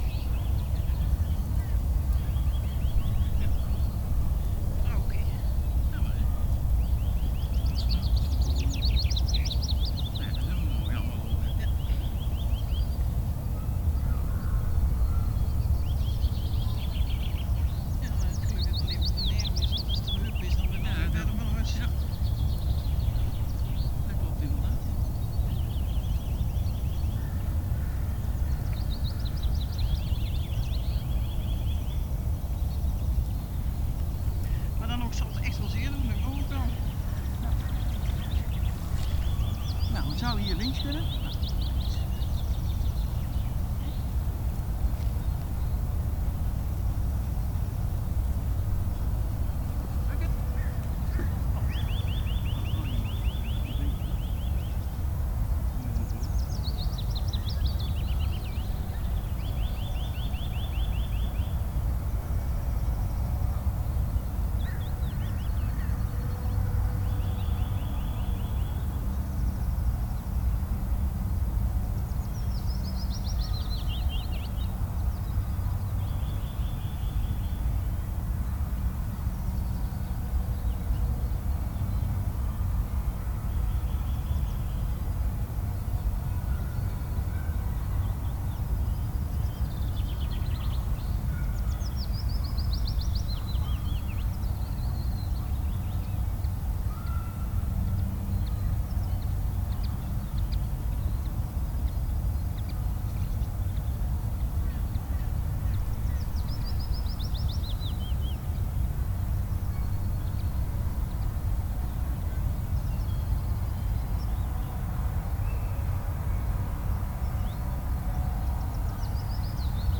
people on drifting sand area Loonse en Drunense Duinen Netherlands 1053 am 250404_1067
Category 🌿 Nature
ambiance ambience ambient atmospheric background-sound birds calm drifting-sand-dunes sound effect free sound royalty free Nature